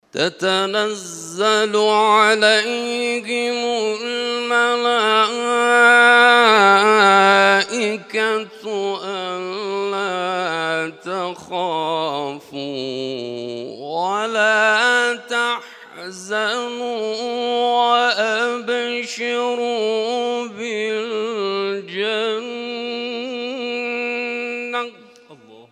تلاوت‌های محفل هفتگی انس با قرآن آستان عبدالعظیم(ع) + دانلود
محفل هفتگی انس با قرآن در آستان عبدالعظیم(ع) + صوت